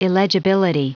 Prononciation du mot illegibility en anglais (fichier audio)
illegibility.wav